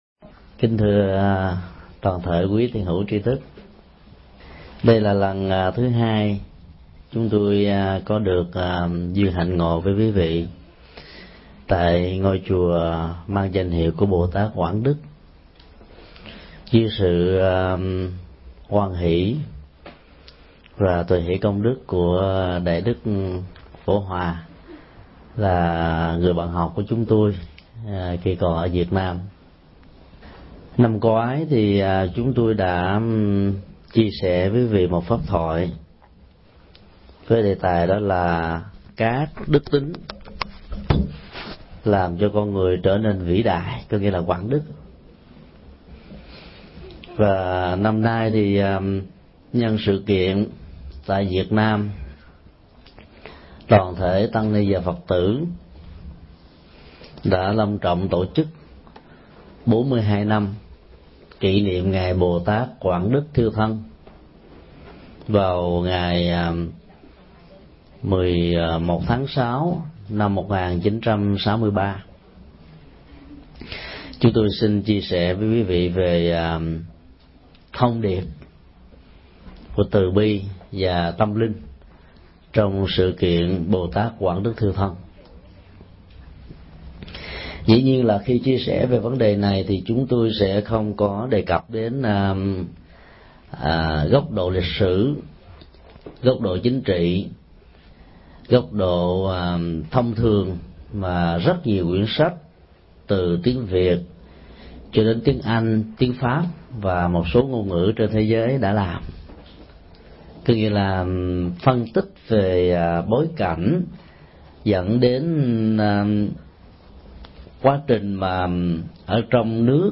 Mp3 Thuyết Pháp Thông điệp Bồ-tát Quảng Đức
Giảng tại Chùa Quảng Đức, San Jose, ngày 19 tháng 6 năm 2005